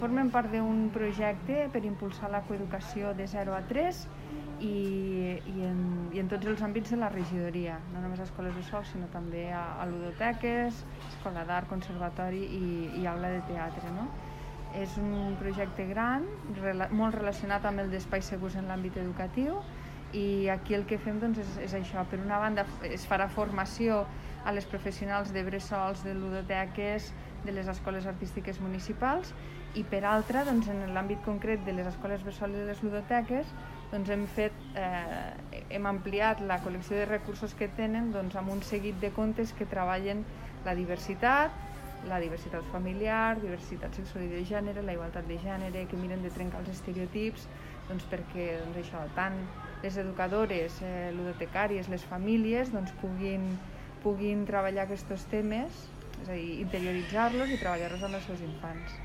tall-de-veu-tinent-dalcalde-sandra-castro-sobre-els-nous-materials-educatius-del-projecte-de-coeducacio